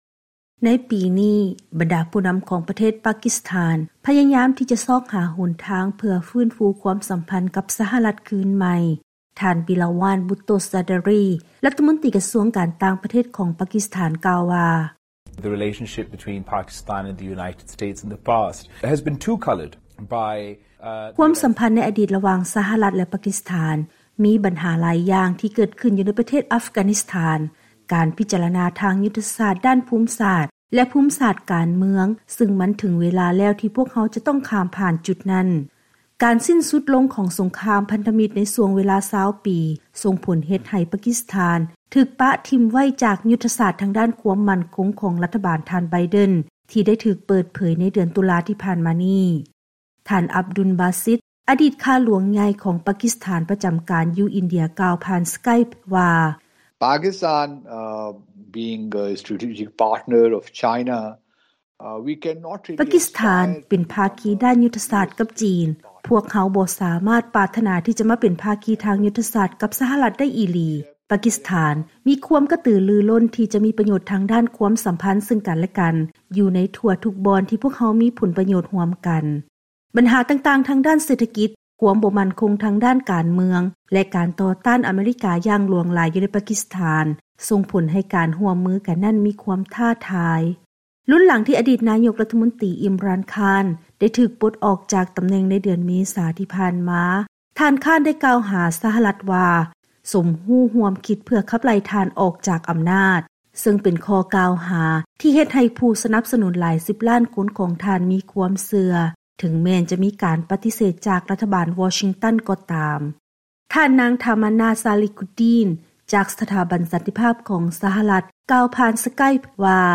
ເຊີນຮັບຟັງລາຍງານກ່ຽວກັບ ການຟື້ນຟູຄວາມສໍາພັນຄືນໃໝ່ ລະຫວ່າງສະຫະລັດ ແລະ ປາກິສຖານ ລຸນຫຼັງສົງຄາມຢູ່ໃນ ອັຟການິສຖານ.